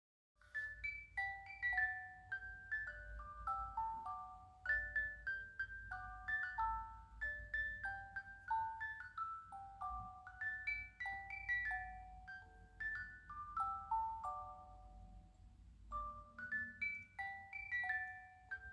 Boite à musique suisse – Pomme
Mouvement: 1x 18 lames
Boite à musique en bois en forme de pomme
alle-vogel-sind-schon-da-18lames.mp3